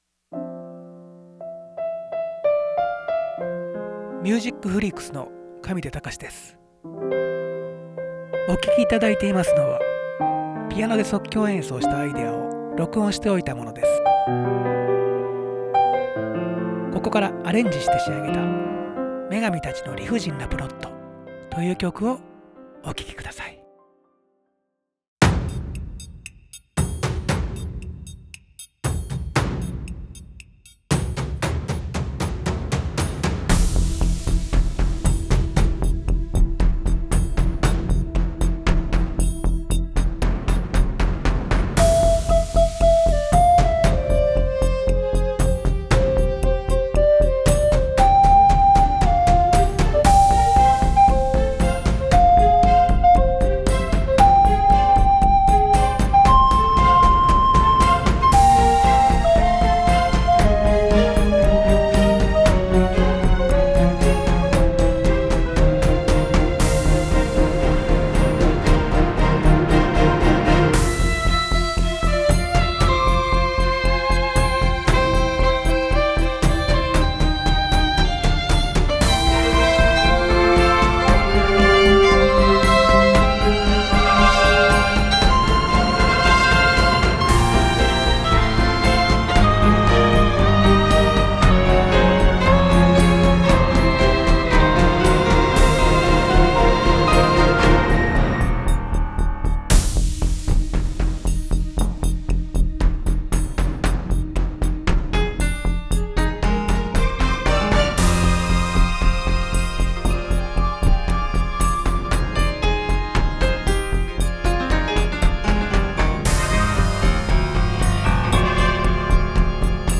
上記動画のメロディを使って、想定したストーリーに合わせて劇伴音楽風に仕上げてみました。
主人公の少女の純粋さを表現するために冒頭の主旋律にはリコーダーを使用しています。アスリートを表現する部分ではパーカッションやブラスを使っています。緊張や不安をイメージする部分を中間部に挟みました。
最後の効果音は市販の素材ではなく自作のもので、曲のBPMに合わせた3連符です。